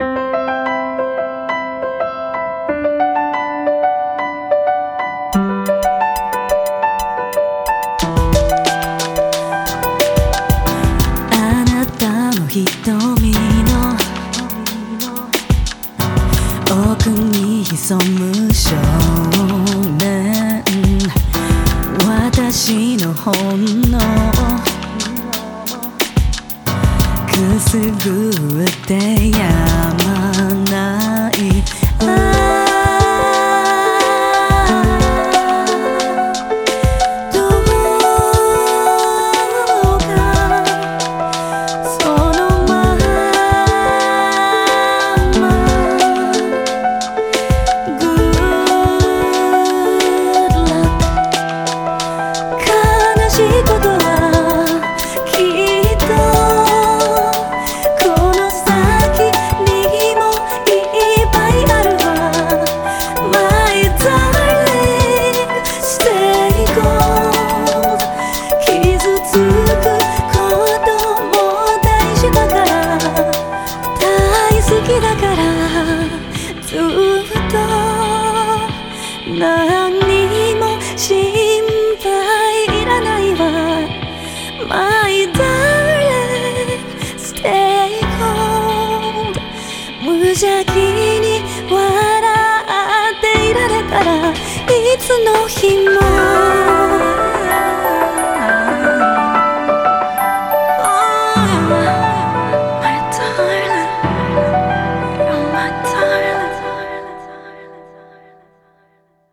BPM90
Audio QualityPerfect (High Quality)
It is a soft R&B-style ballad with piano accompaniment.